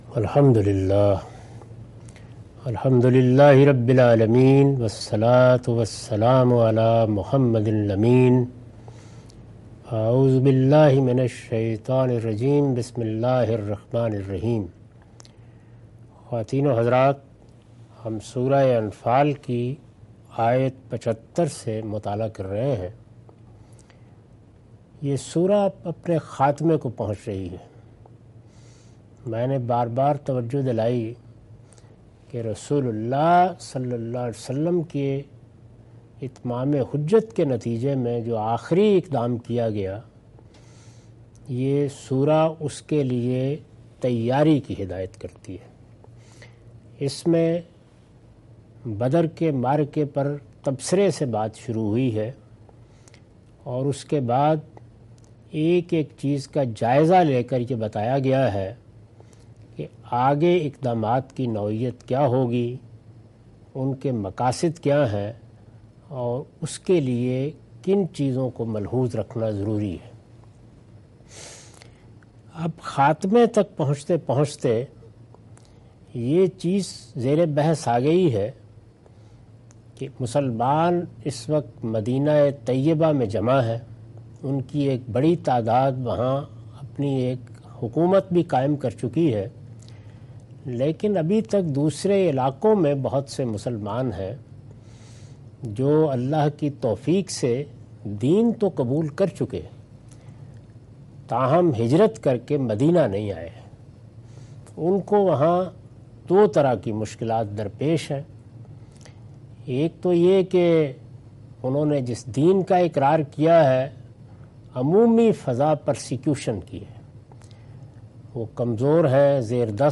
Surah Al-Anfal - A lecture of Tafseer-ul-Quran – Al-Bayan by Javed Ahmad Ghamidi. Commentary and explanation of verses 72-75.